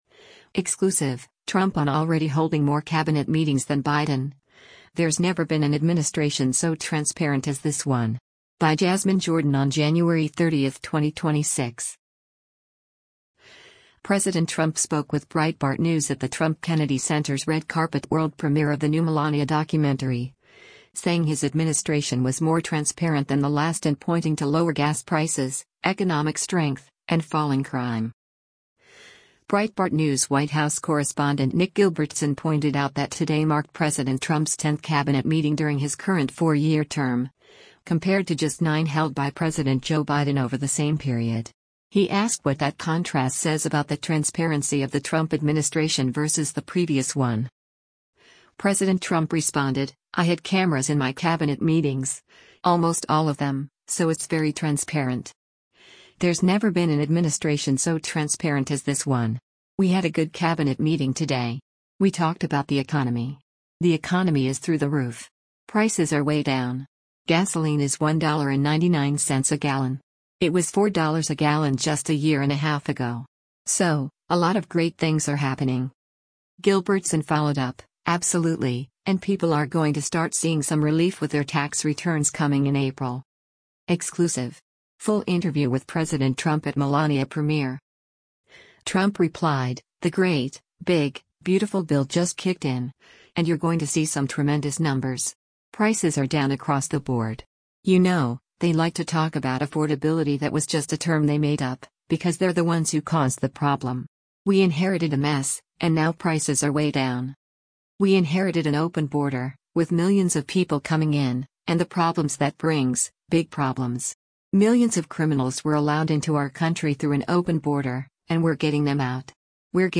President Trump spoke with Breitbart News at the Trump-Kennedy Center’s red carpet world premiere of the new Melania documentary, saying his administration was more transparent than the last and pointing to lower gas prices, economic strength, and falling crime.
EXCLUSIVE! Full Interview with PRESIDENT TRUMP at “Melania” Premiere